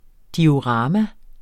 Udtale [ dioˈʁɑːma ]